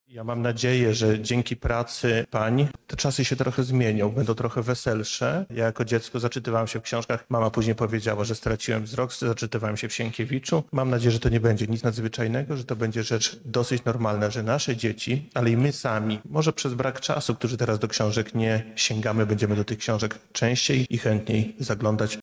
-mówi Bartłomiej Bałaban, członek Zarządu Województwa Lubelskiego